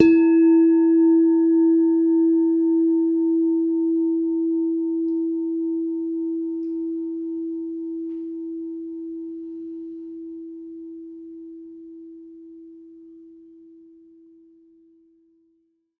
mono_bell_-10_E_16sec
bell bells bell-set bell-tone bong ding dong ping sound effect free sound royalty free Sound Effects